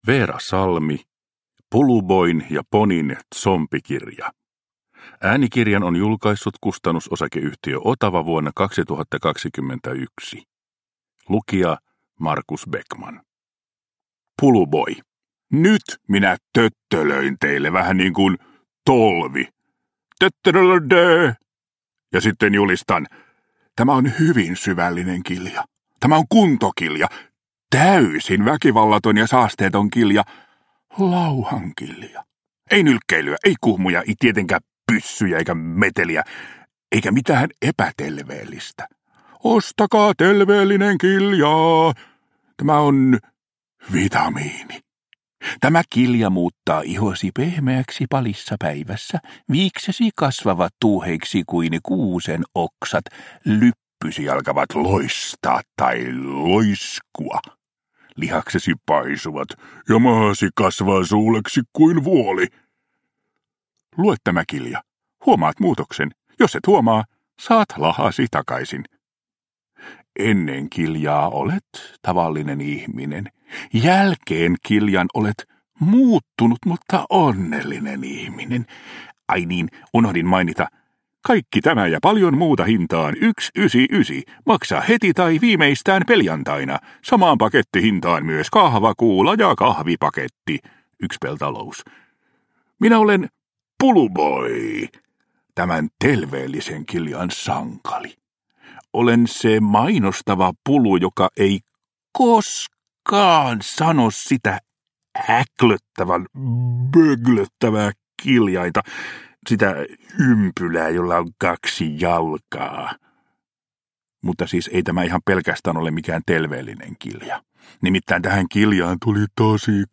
Puluboin ja Ponin tsompikirja – Ljudbok – Laddas ner